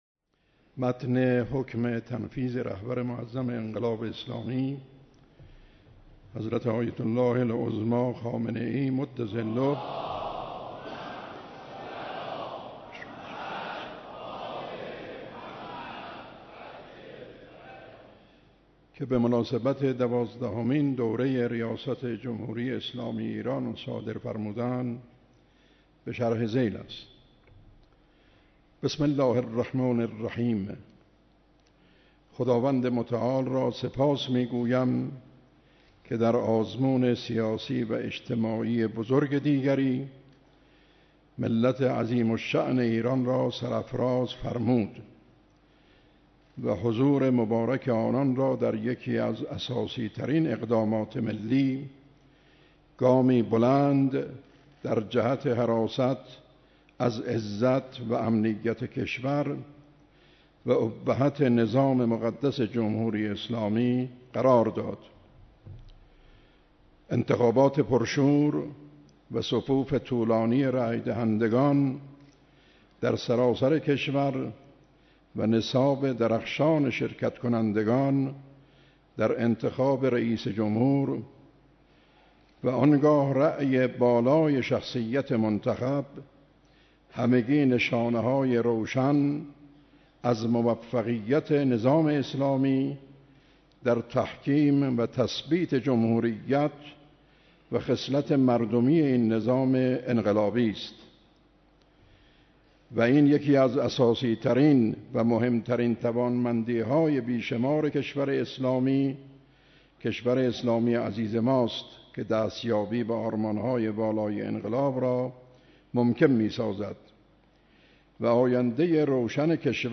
مراسم تنفیذ حکم ریاست جمهوریِ حجت‌الاسلام دکتر روحانی
قرائت حکم تنفیذ دوازدهمین دوره ی ریاست جمهوری توسط حجت الاسلام و المسلمین محمدی گلپایگانی